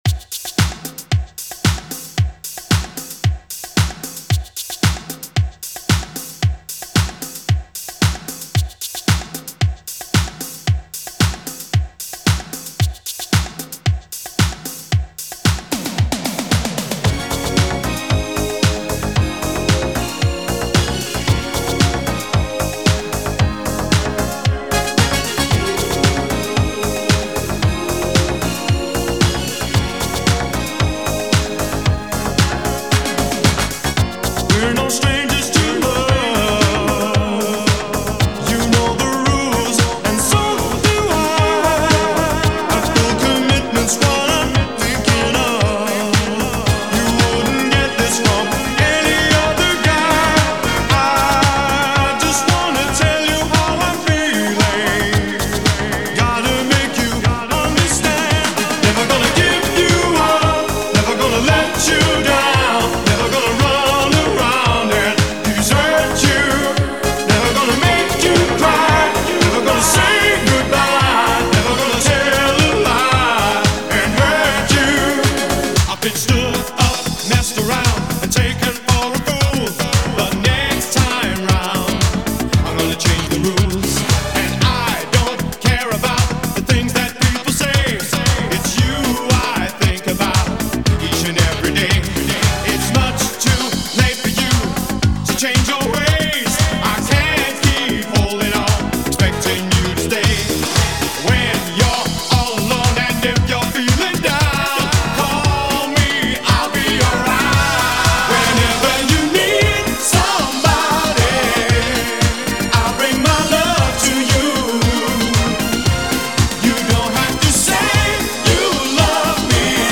Feel the Beat and Dance All Night in Miami
high-energy megamix
unstoppable club rhythms